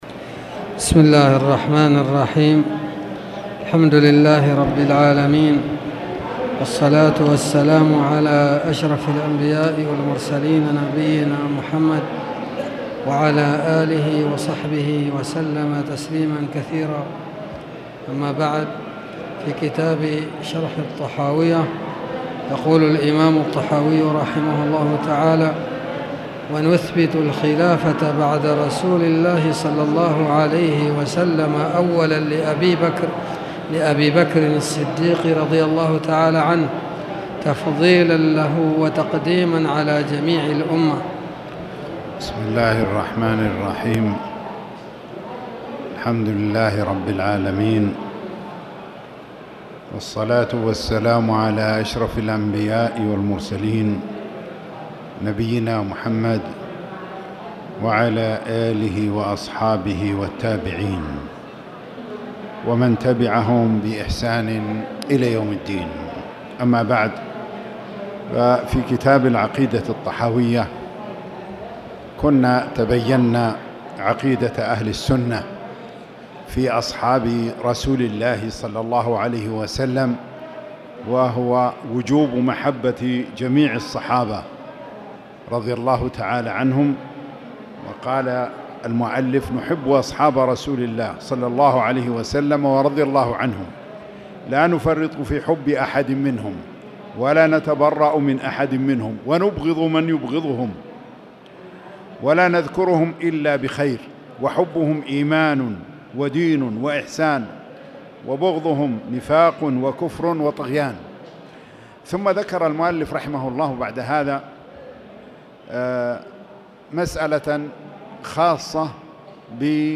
تاريخ النشر ٧ صفر ١٤٣٨ هـ المكان: المسجد الحرام الشيخ